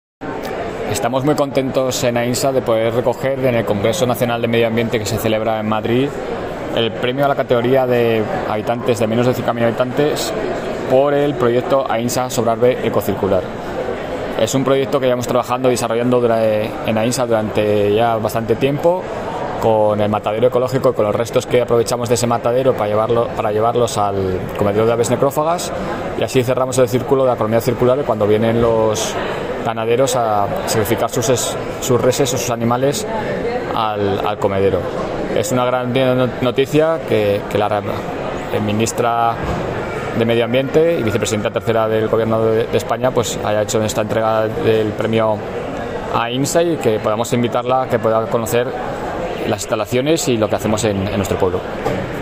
Declaraciones Enrique Pueyo
Declaraciones-Enrique-Pueyo.mp3